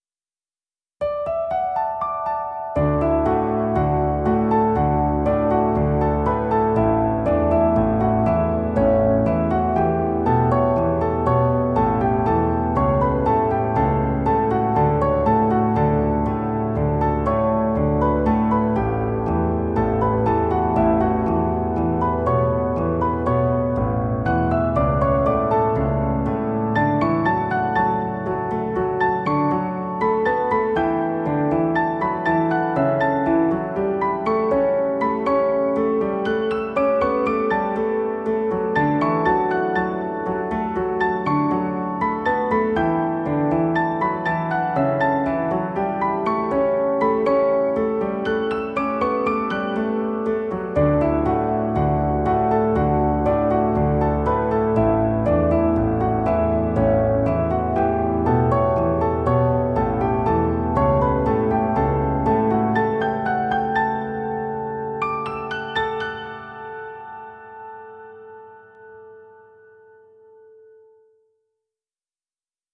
music / PIANO D-G